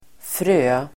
Uttal: [frö:]